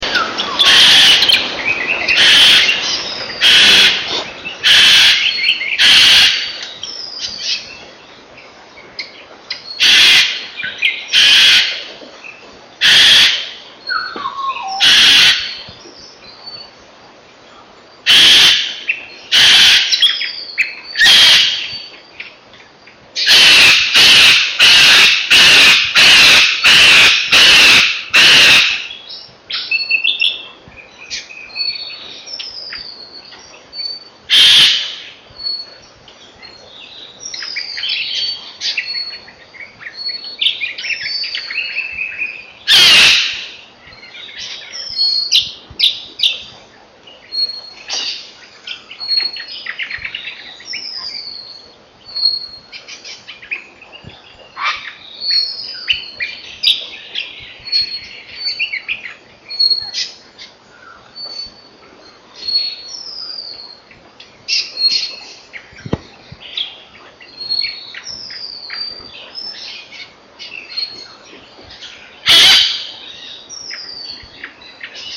Yellow-crested Cockatoo
Voice.  Typical calls are harsh screeches:
Bipolo, West Timor, Indonesia; October 8, 1995.